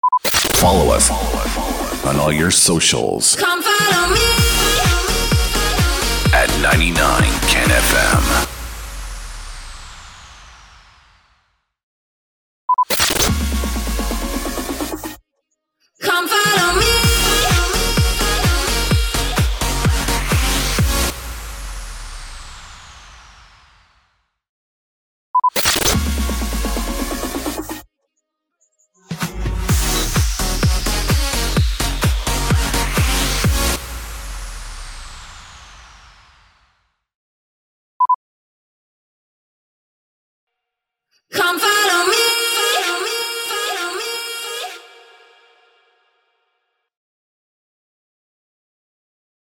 795 – SWEEPER – SOCIAL MEDIA
795-SWEEPER-SOCIAL-MEDIA.mp3